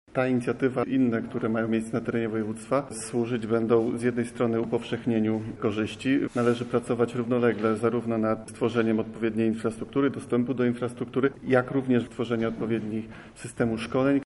Porozumienie podpisano podczas debaty dotyczącej rozwoju sieci szerokopasmowego Internetu w Polsce Wschodniej.
O korzyściach wynikających z inicjatywy mówi Marceli Niezgoda, podsekretarz w Ministerstwie Infrastruktury i Rozwoju